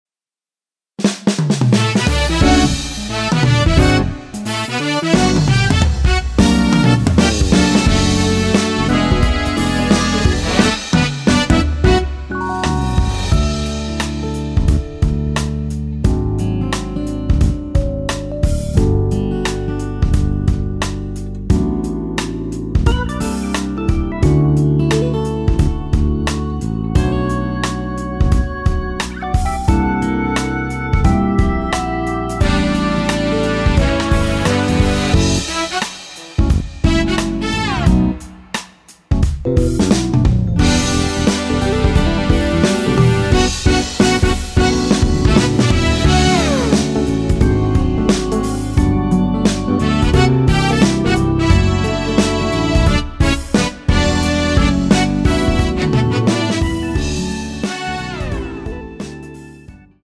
(-1) 내린 MR
◈ 곡명 옆 (-1)은 반음 내림, (+1)은 반음 올림 입니다.
앞부분30초, 뒷부분30초씩 편집해서 올려 드리고 있습니다.
중간에 음이 끈어지고 다시 나오는 이유는